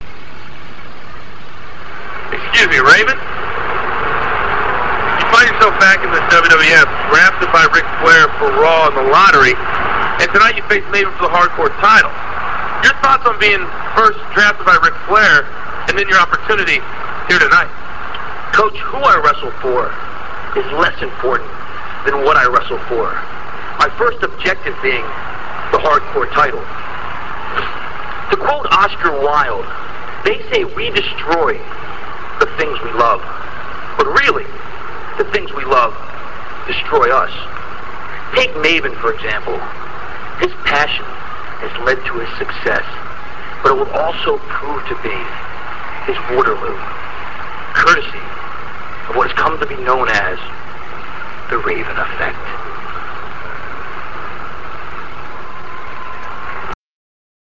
- This clip comes from WWF Smackdown - [03.26.02]. Jonathan Coachman finds Raven backstage and asks him about being drafted by Ric Flair and his thoughts on the Hardcore Title.